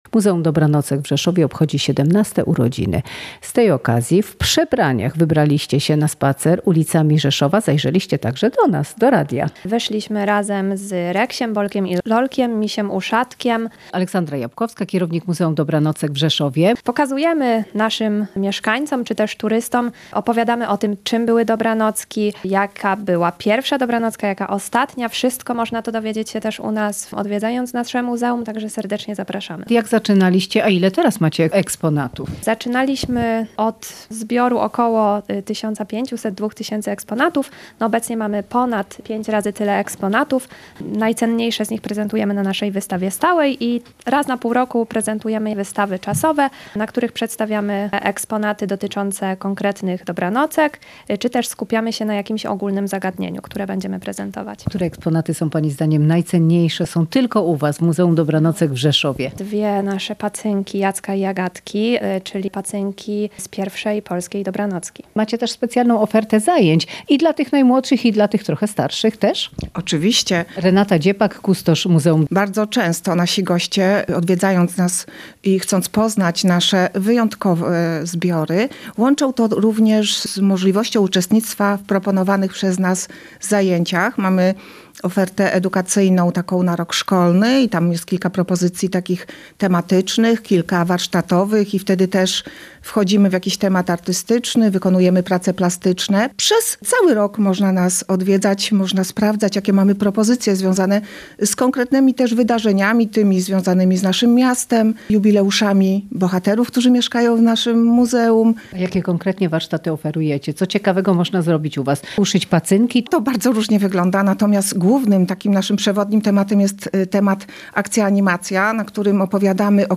Relacje reporterskie • Reksio oraz Bolek i Lolek pojawili się dziś (17.03) na ulicach Rzeszowa, odwiedzając także Radio Rzeszów.